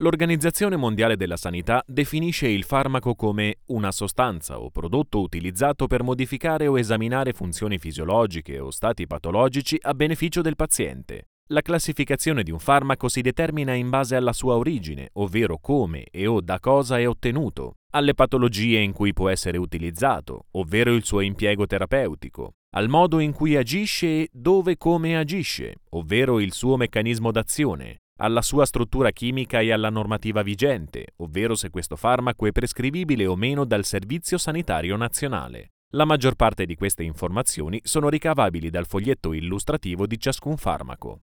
Voce baritonale e calda.
Sprechprobe: eLearning (Muttersprache):
Warm voice, precision, speed and efficacy.